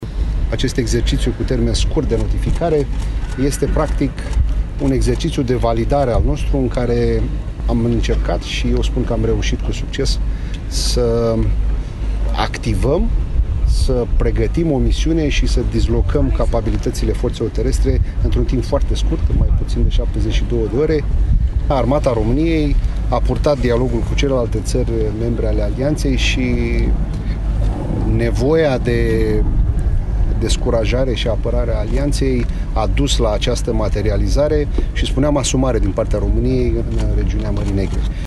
Generalul maior Iulian Berdilă, șeful Forțelor Terestre: